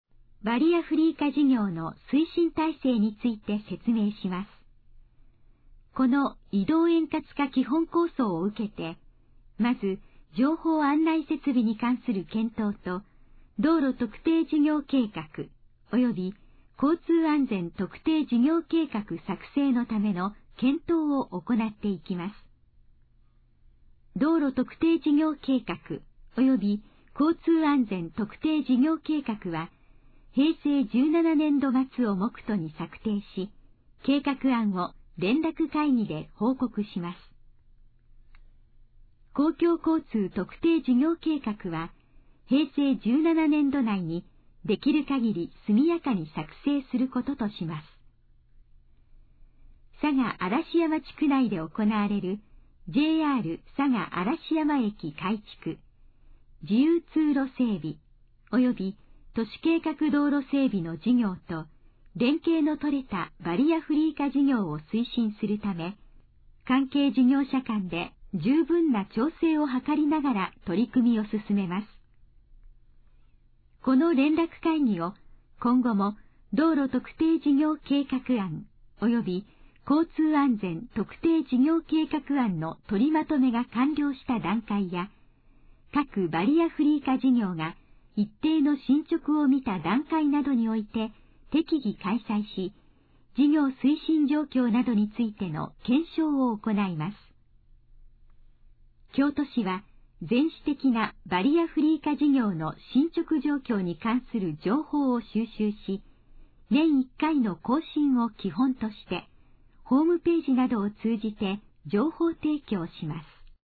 このページの要約を音声で読み上げます。
ナレーション再生 約222KB